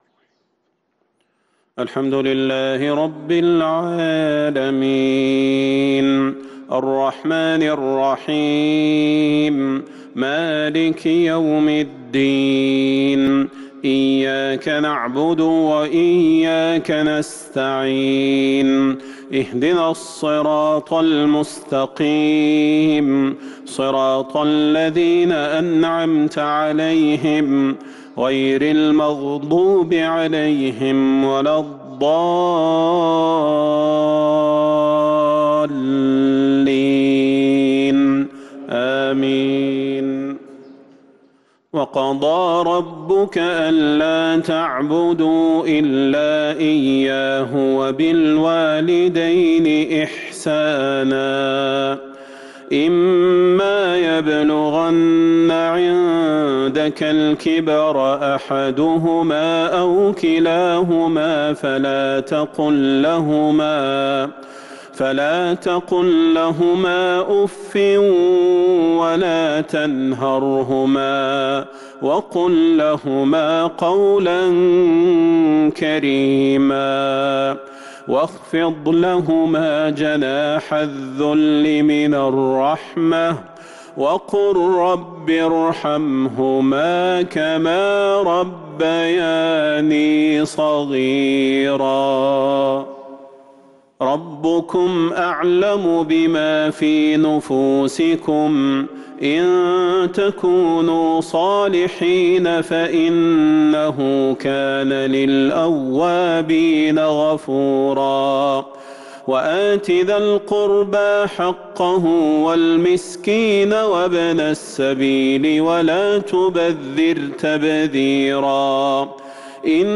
مغرب السبت 2-8-1443هـ من سورة الإسراء | 5-3-2022 Maghreb prayer from Surah Al-Isra > 1443 🕌 > الفروض - تلاوات الحرمين